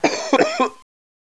cough3.wav